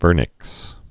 (vûrnĭks)